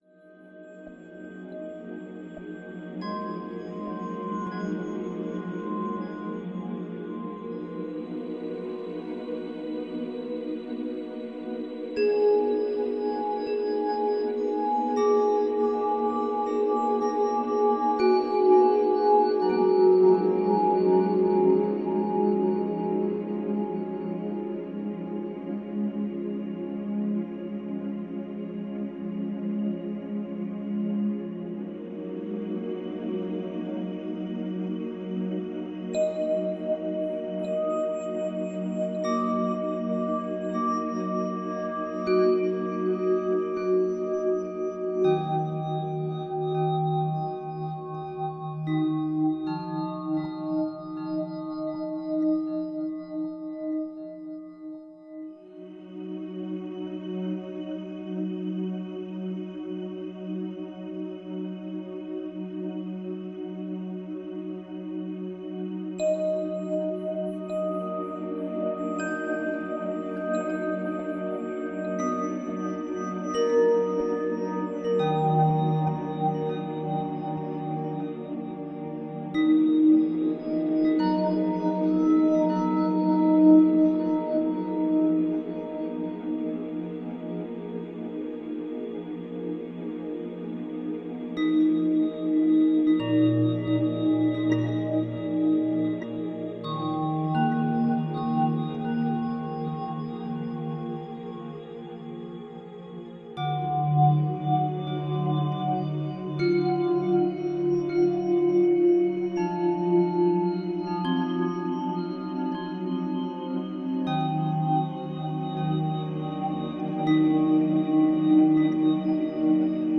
Музыка природы